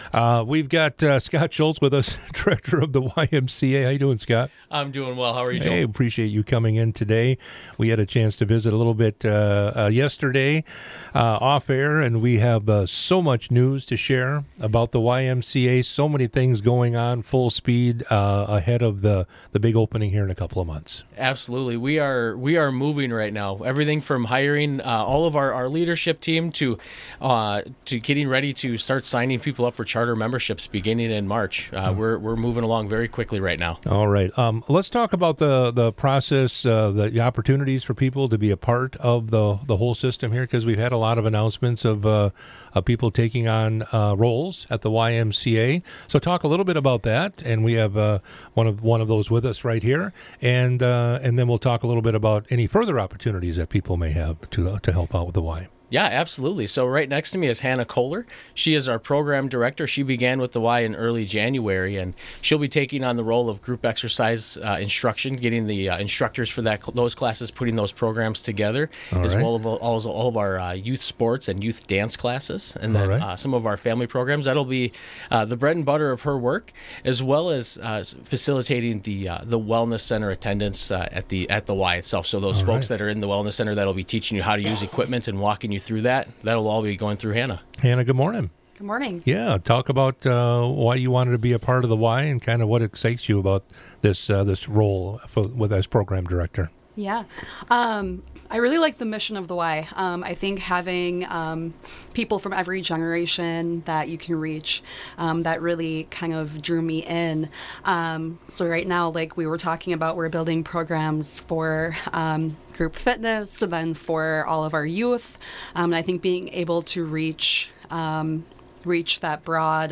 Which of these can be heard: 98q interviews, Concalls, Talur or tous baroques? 98q interviews